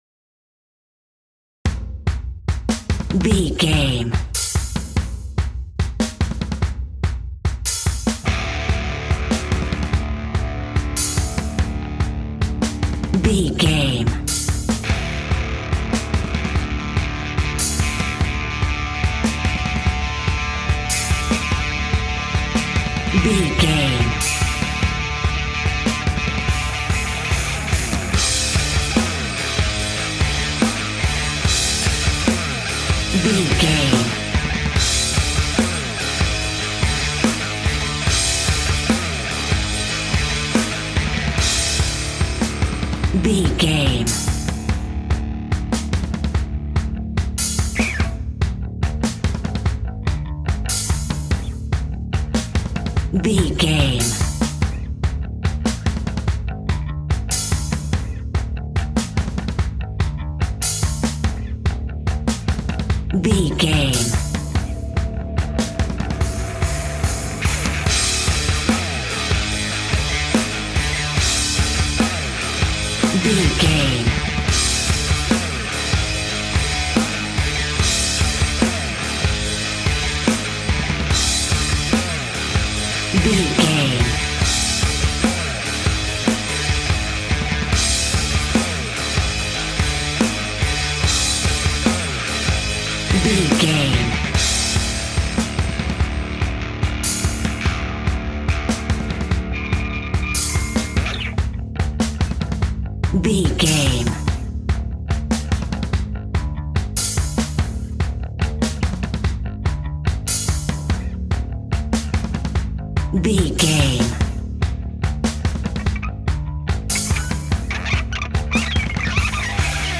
Ionian/Major
Fast
energetic
driving
heavy
aggressive
electric guitar
bass guitar
drums
hard rock
heavy metal
scary rock
rock instrumentals